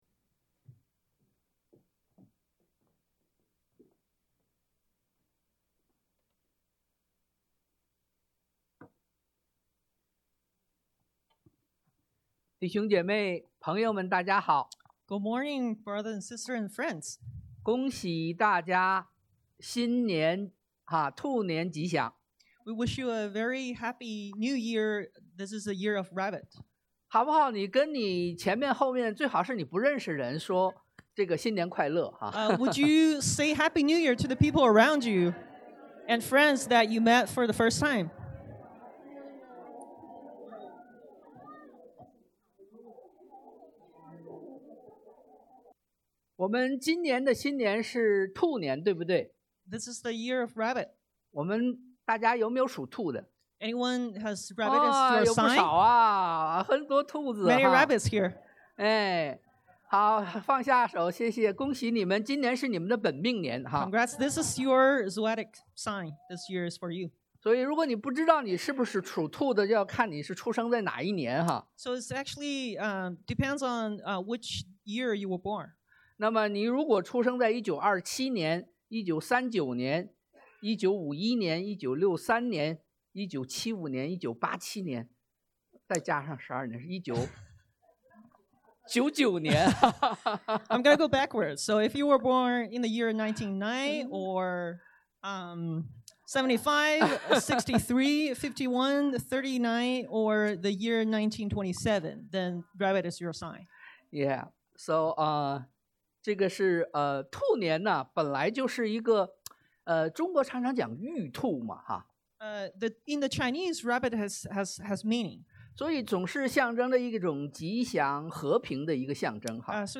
Service Type: Special